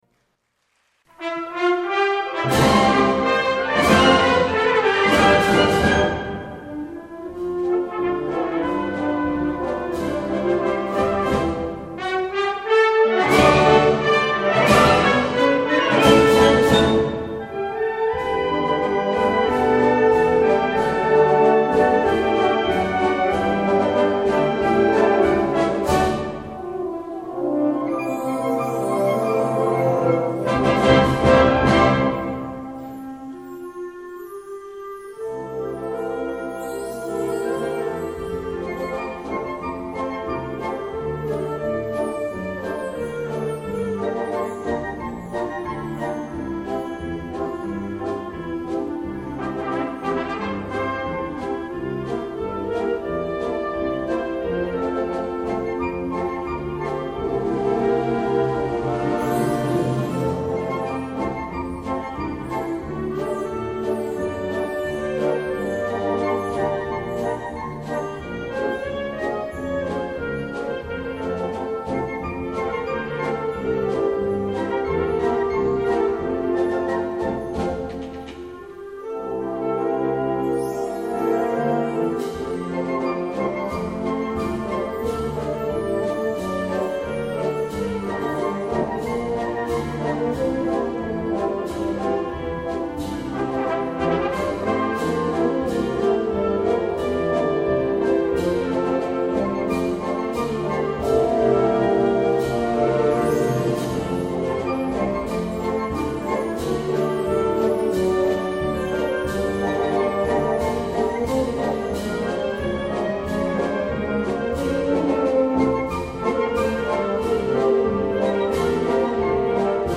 Pasdoble